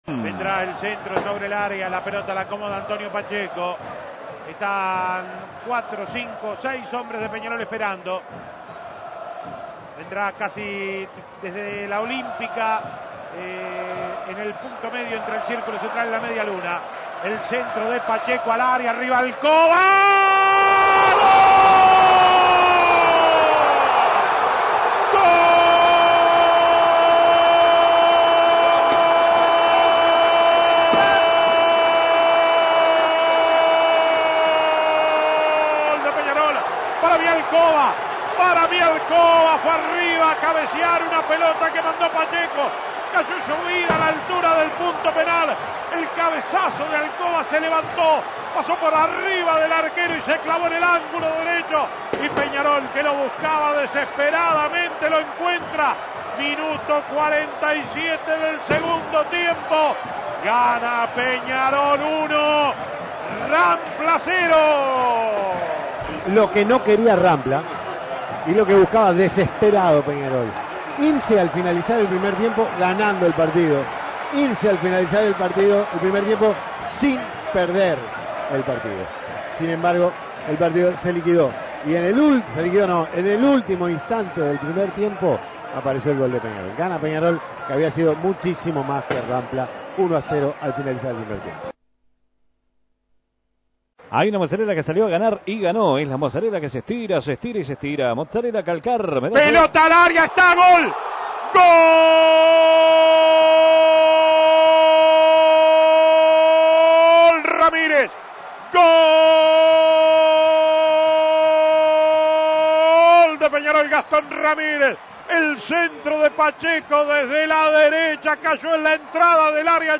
Goles y comentarios Escuche los goles de Peñarol ante Rampla Juniors Imprimir A- A A+ Los aurinegros derrotaron a Rampla Juniors 2 a 0 y llegaron a la novena victoria consecutiva.